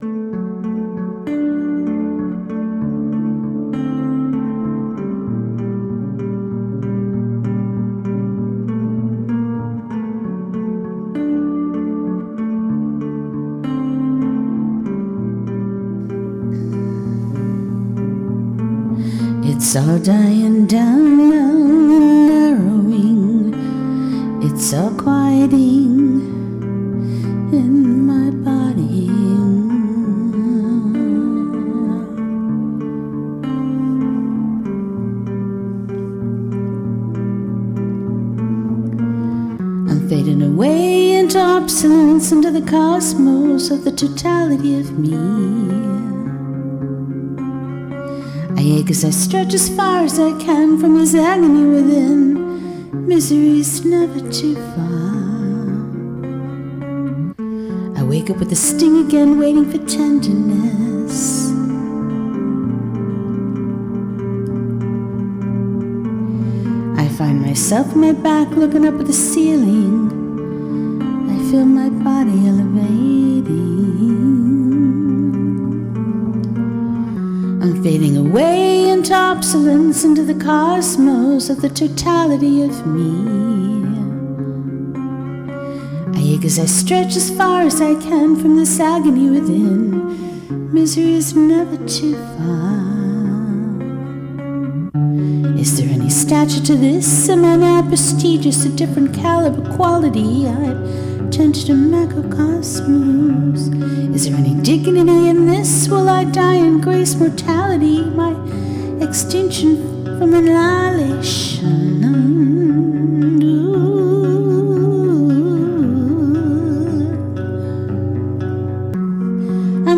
It's all here to be inspired by my music and a bit of conversation.